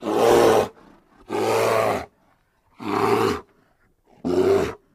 Kodiak Bear Growls, Even Spaced